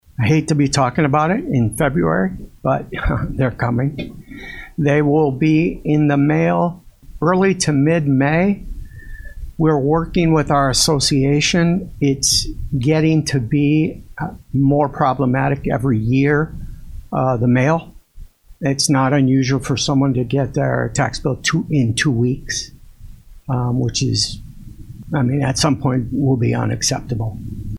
During Thursday morning’s meeting of the Kankakee County Board’s Finance Committee, Country Treasurer Nick Africano gave the committee and update on when tax bills should be going out this year.